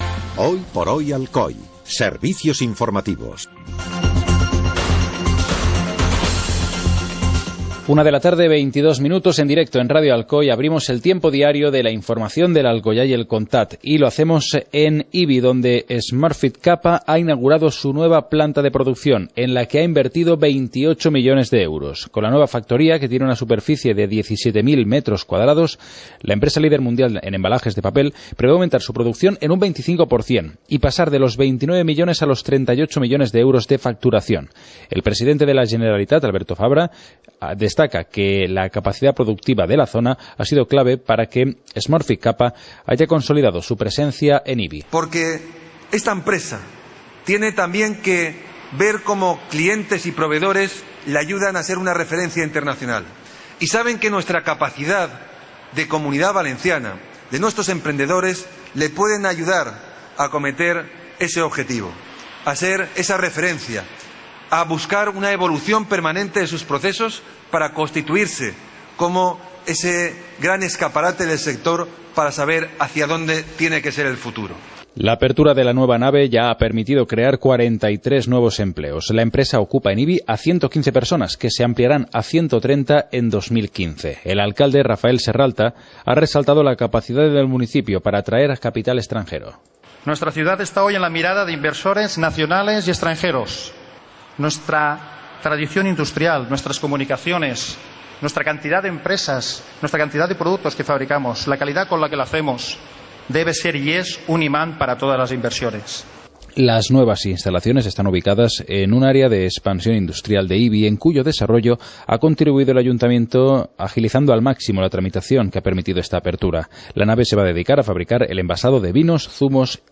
Informativo comarcal - miércoles, 19 de noviembre de 2014